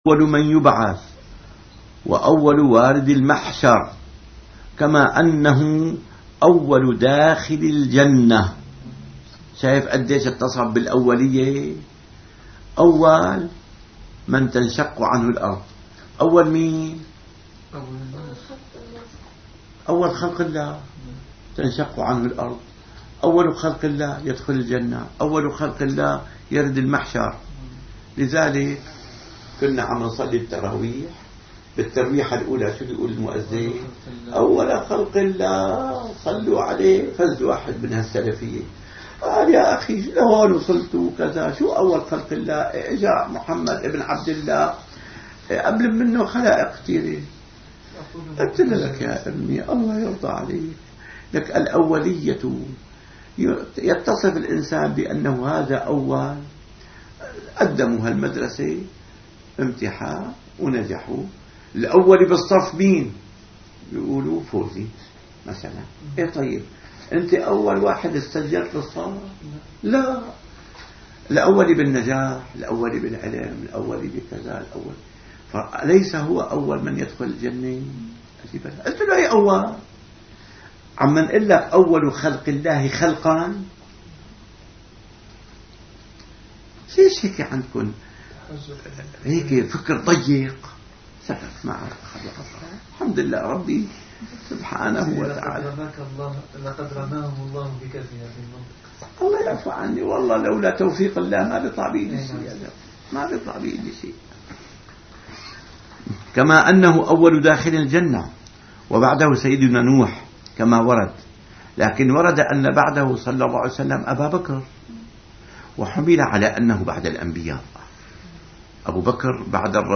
شرح جوهرة التوحيد - - الدروس العلمية - العقيدة الإسلامية - الدرس الثالث والثلاثون